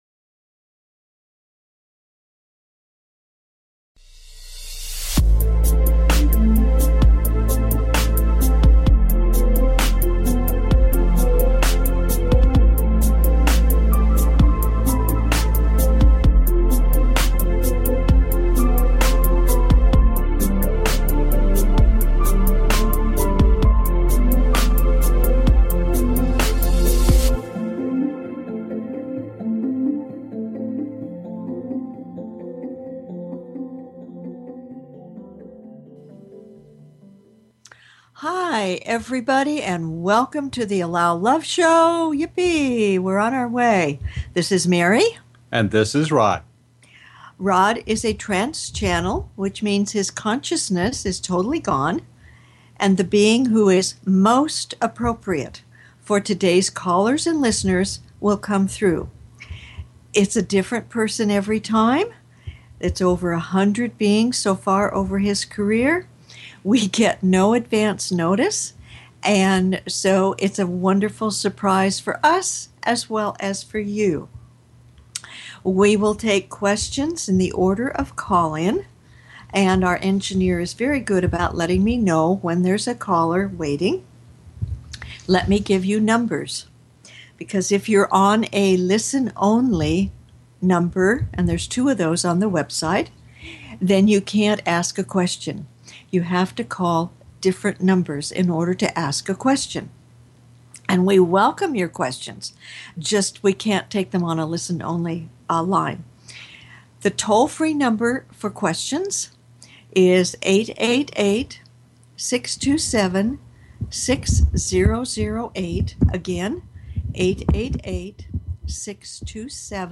Talk Show Episode, Audio Podcast, The Allow Love Show and with Merlin on , show guests , about Merlin,Wizard,Magician, categorized as Paranormal,Ghosts,Philosophy,Spiritual,Access Consciousness,Medium & Channeling